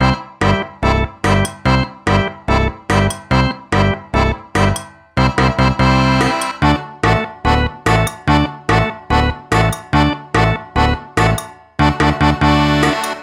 上司に圧力をかけられているような、ちょっと嫌な感じに急かされて気が重くなるループできるストレスジングル。